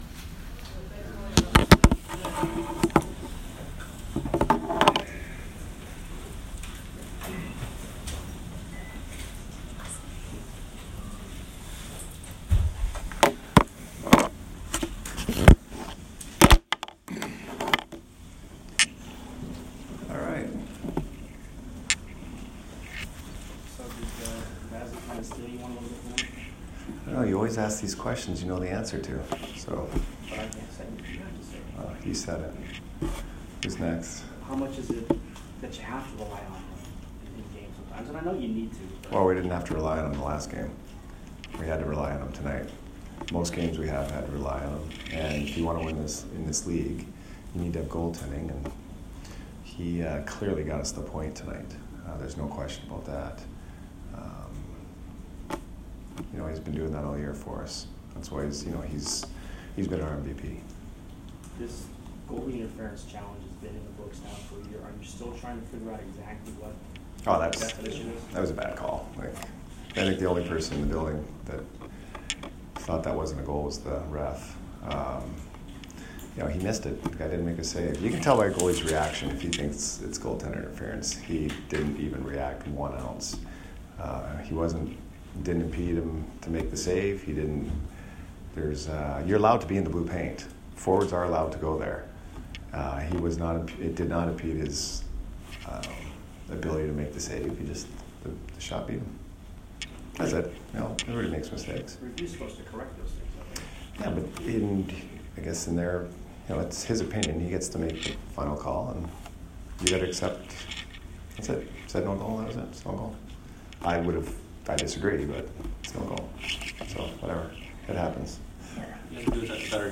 Jon Cooper Post-Game 11/2